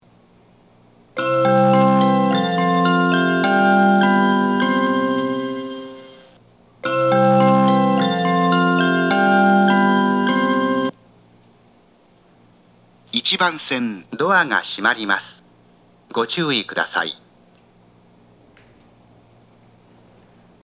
発車メロディーは南武線オリジナルメロディーです。
発車メロディー
1.9コーラスです。
曲が短いので日中でも複数コーラス鳴りやすいです。
TOA標準型（1番線）